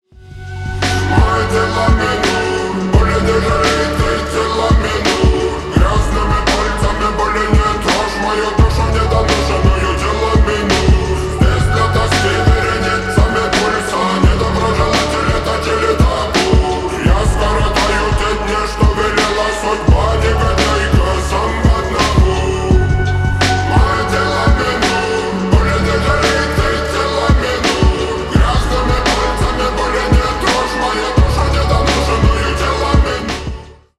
• Качество: 320 kbps, Stereo
Ремикс
Рэп и Хип Хоп